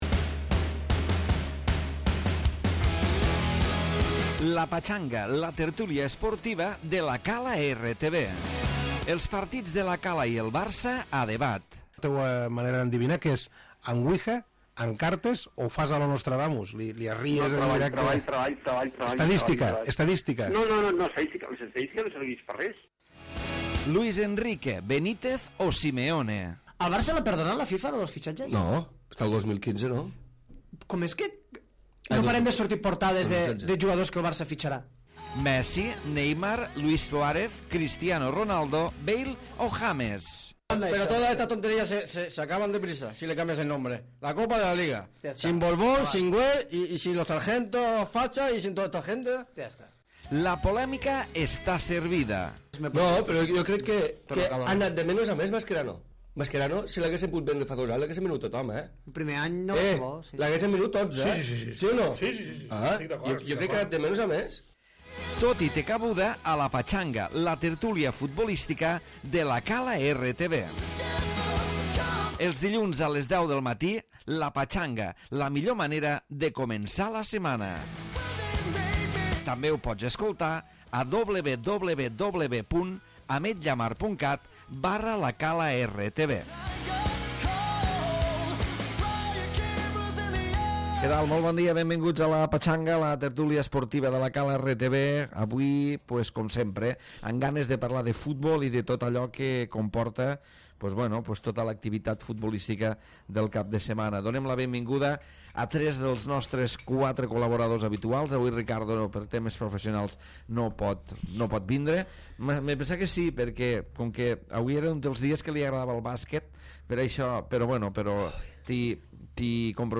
Tertúlia d'actualitat futbolística